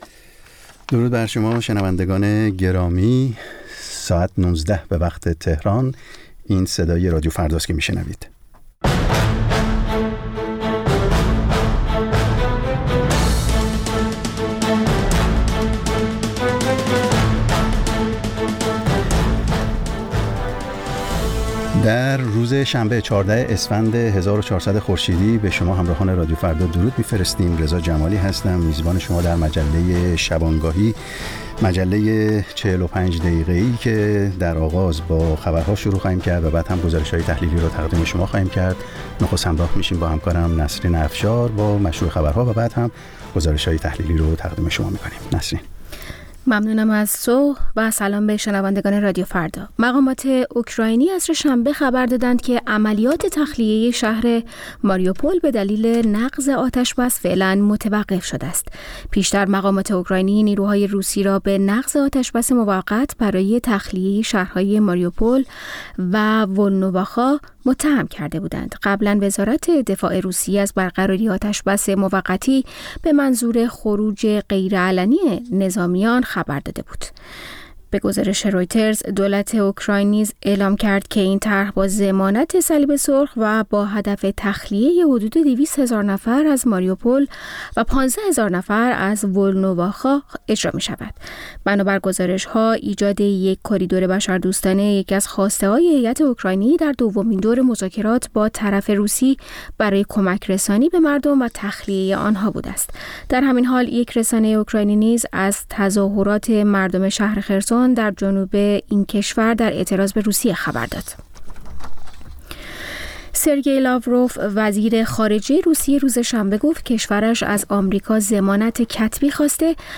در نیم ساعت اول مجله شامگاهی رادیو فردا، آخرین خبرها و تازه‌ترین گزارش‌های تهیه‌کنندگان رادیو فردا پخش خواهد شد. در نیم ساعت دوم شنونده یکی از مجله‌های هفتگی رادیو فردا خواهید بود.